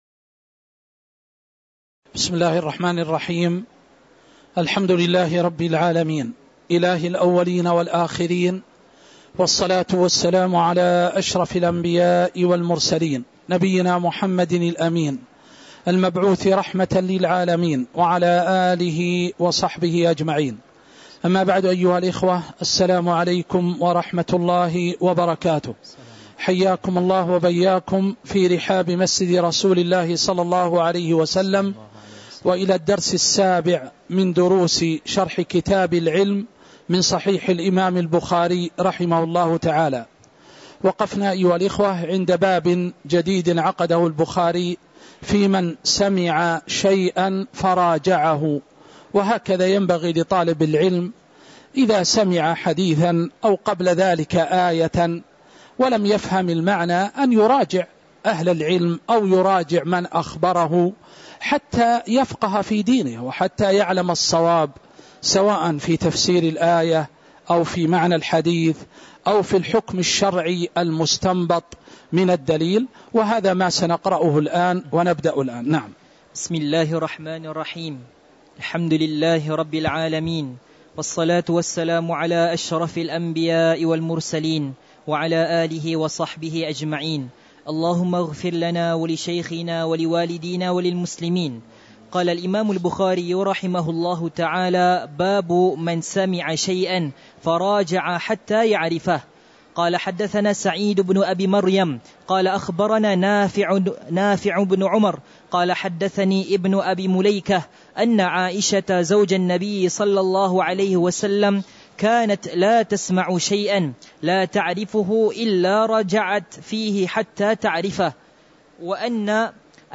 تاريخ النشر ٢٢ محرم ١٤٤٦ هـ المكان: المسجد النبوي الشيخ